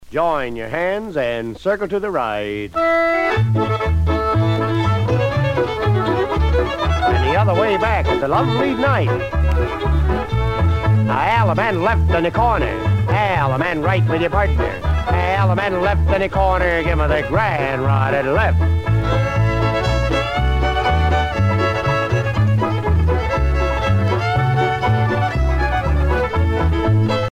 danse : square dance
Pièce musicale éditée